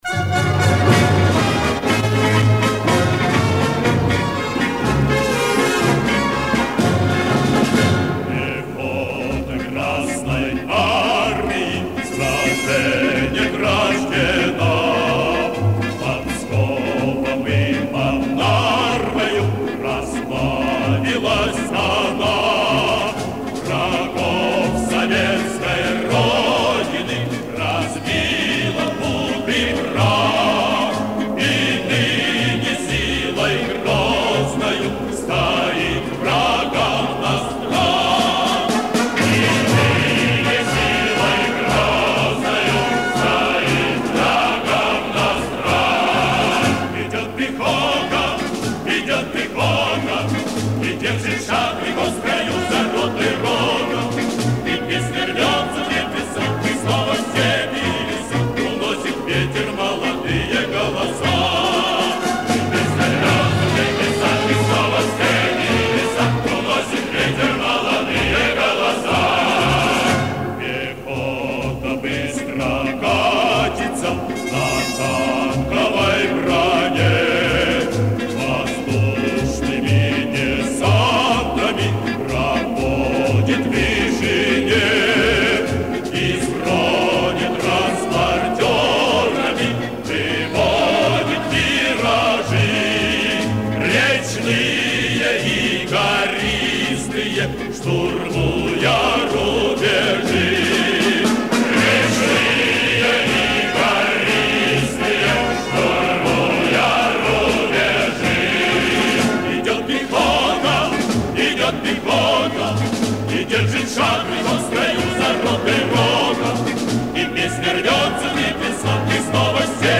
Военные песни